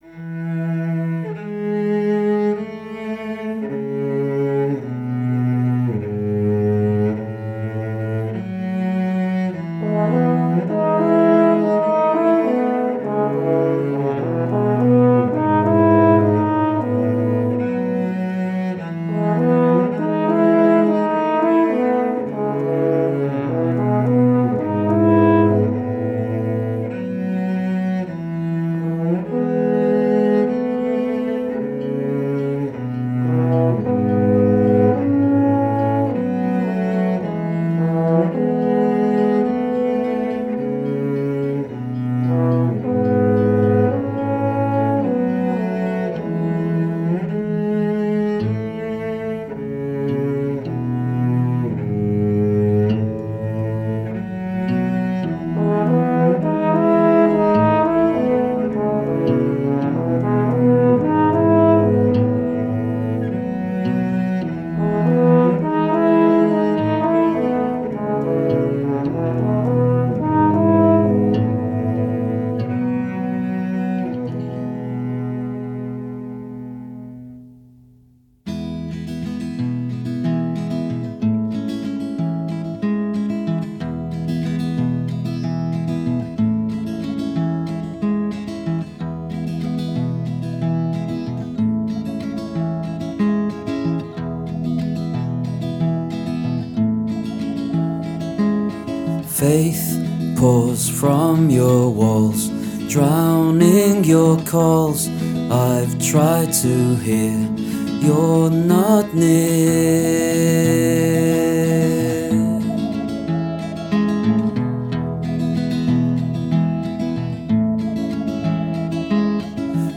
This track is tagged Folk/Rock.